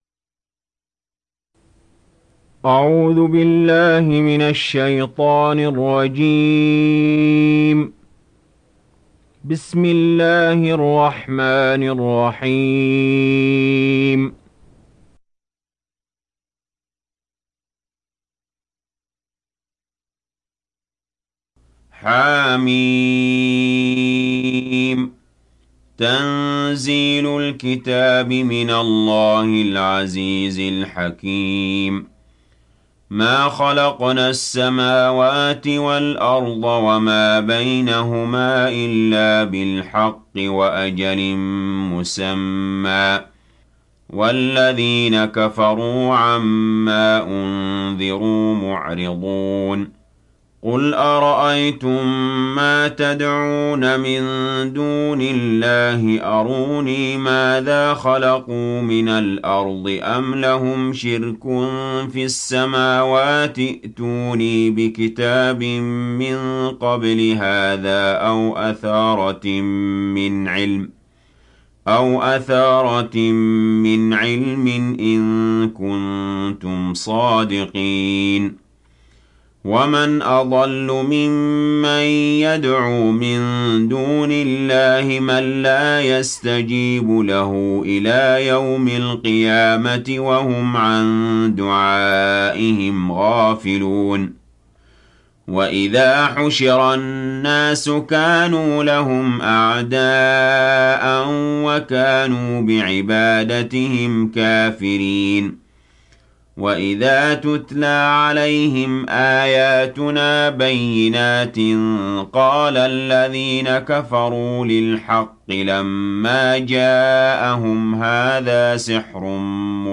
تحميل سورة الأحقاف mp3 بصوت علي جابر برواية حفص عن عاصم, تحميل استماع القرآن الكريم على الجوال mp3 كاملا بروابط مباشرة وسريعة